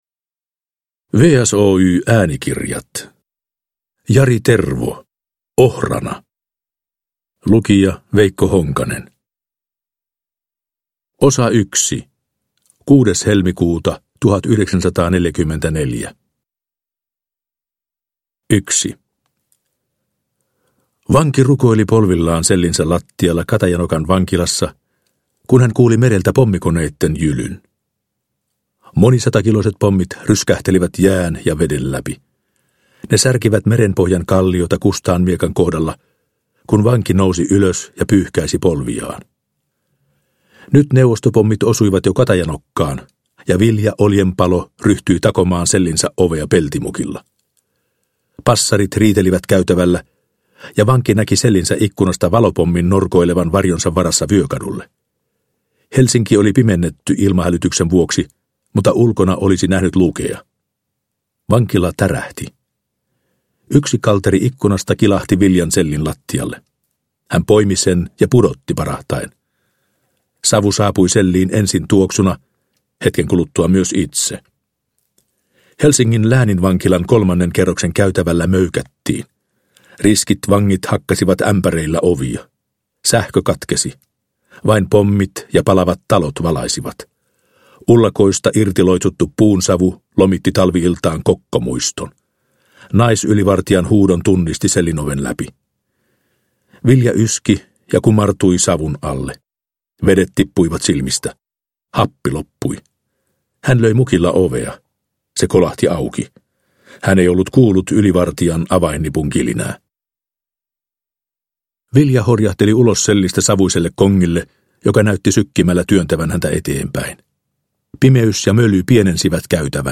Ohrana – Ljudbok – Laddas ner